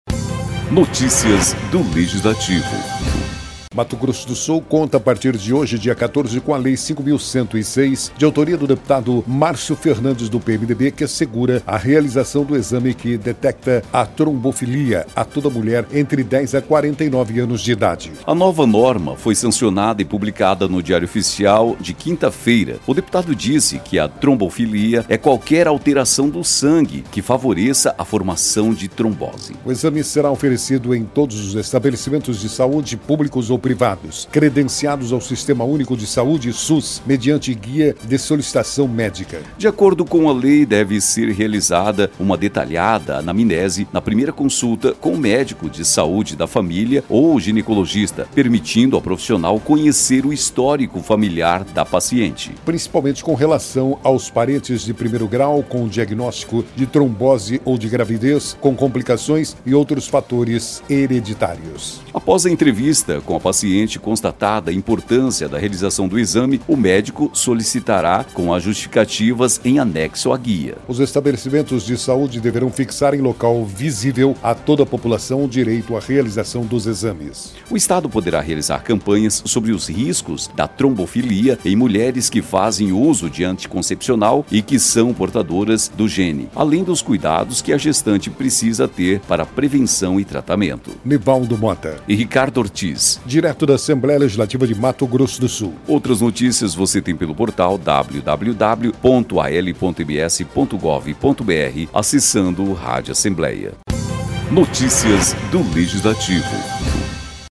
“A trombofilia é qualquer alteração do sangue que favoreça a formação de trombose. A formação de coágulo nas pernas, em casos graves e não tratados, pode evoluir para uma embolia pulmonar e levar à morte”, disse o deputado.
Locução: